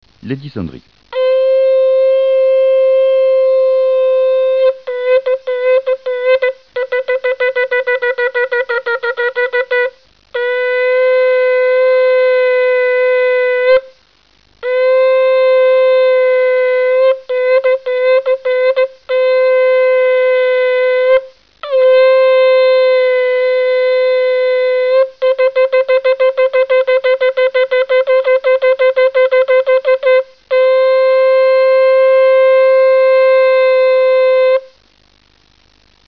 Le Choffar
sonneries de Roch Hachanah cumulent dix sons .
Un ensemble "Tekiyah" - "Chevarim-Terouah" - "Tekiyah" (Ta CH Ra T)
Un ensemble "Tekiyah" - "Chevarim" - "Tekiyah"(Ta CH T)
10sonnerie.mp3